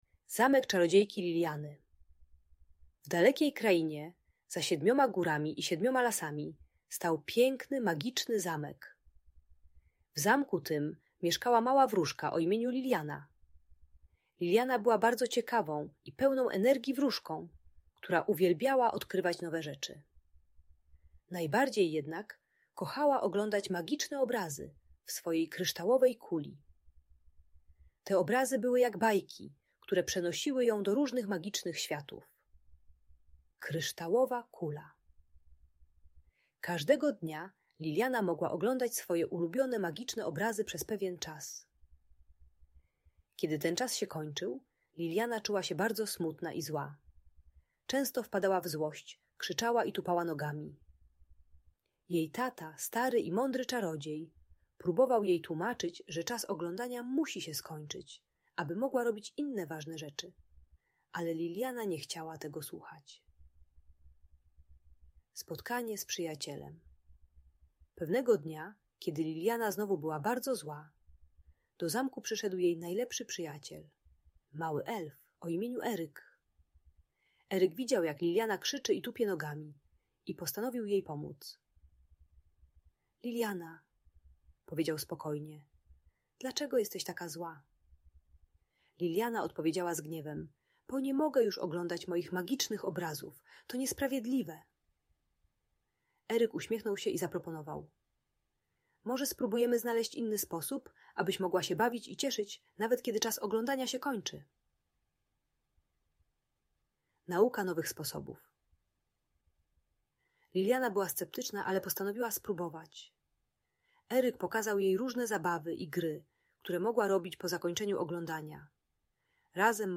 Magiczna historia wróżki Liliany - Audiobajka